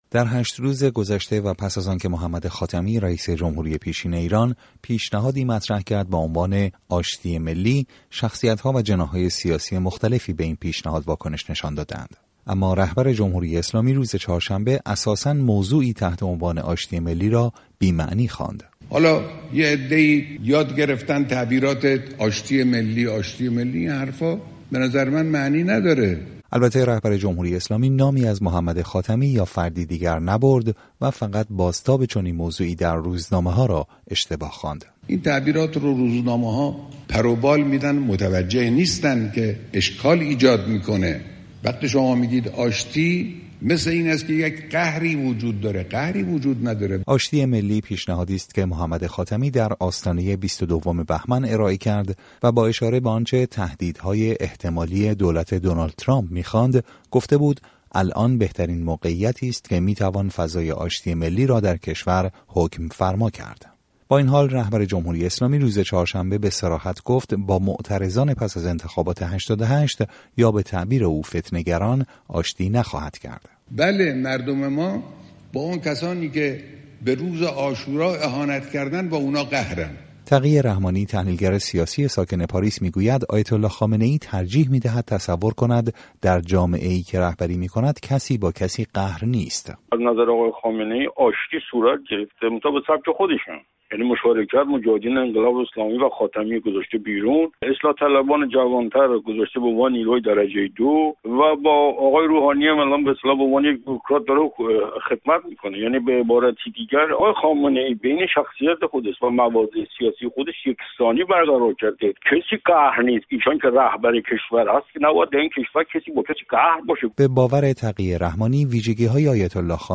گزارش تحلیلی